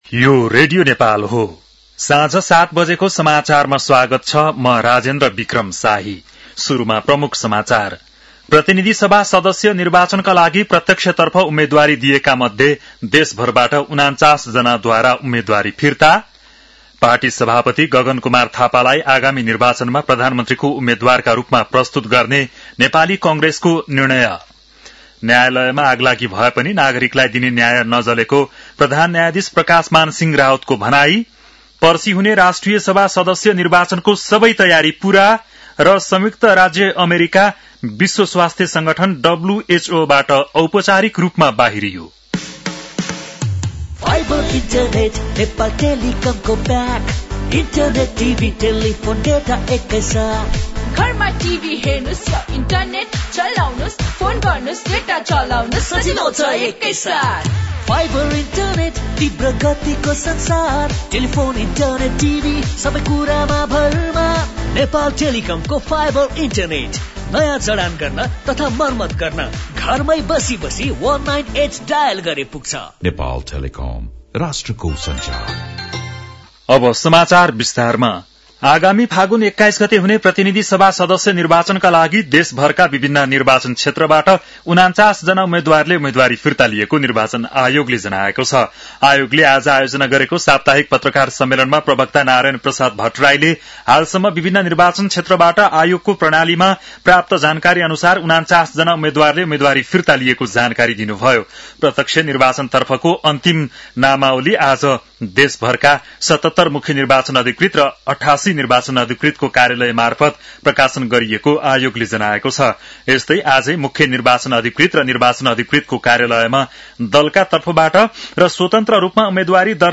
बेलुकी ७ बजेको नेपाली समाचार : ९ माघ , २०८२
7-pm-nepali-news-10-09.mp3